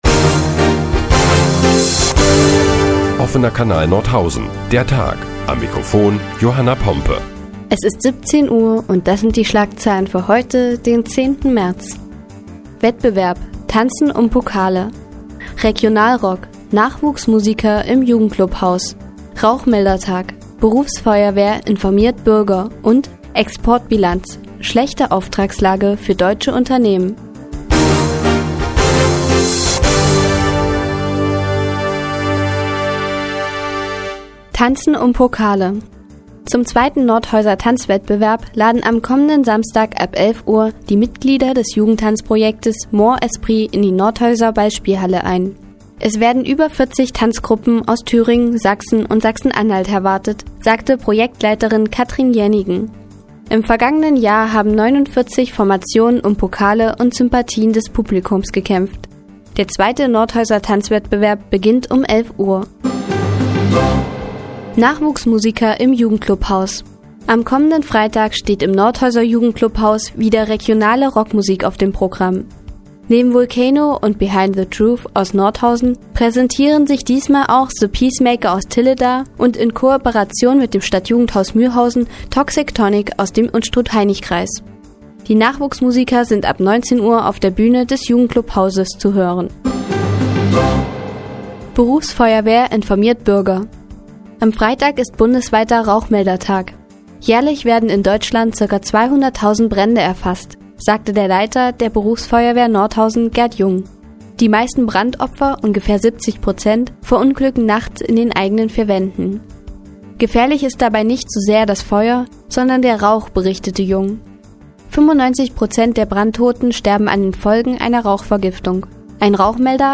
Die tägliche Nachrichtensendung des OKN ist nun auch in der nnz zu hören. Heute geht es unter anderem um Nachwuchsmusiker im Jugendclubhaus und den bundesweiten Rauchmeldertag.